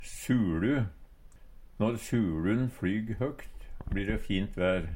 suLu - Numedalsmål (en-US)